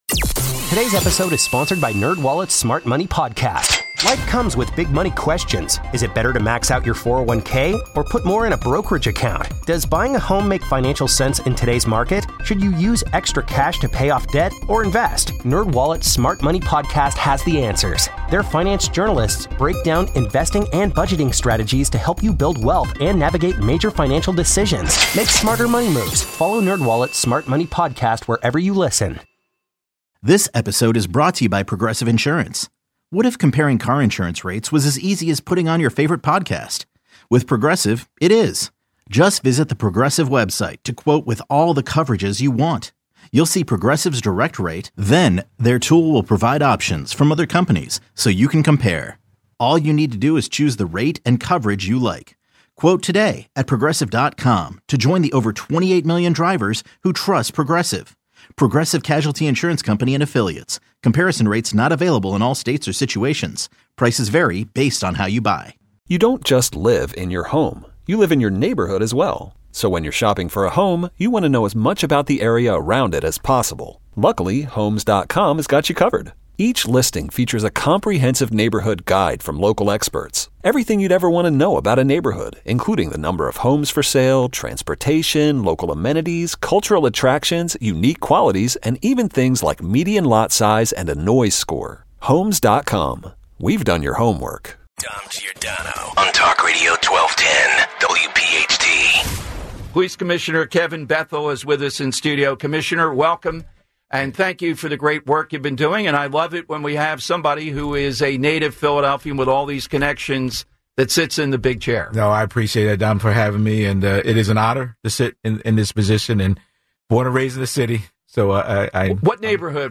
PPD Commish Bethel Swings Into Studio, Breaks Down Kensington Plan